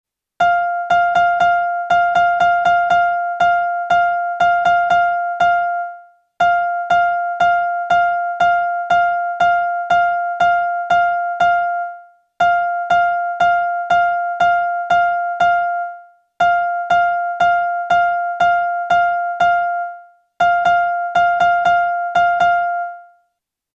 As spoken poetry, the natural rhythm would sound like this, with long syllables shown as crotchets and short syllables as quavers: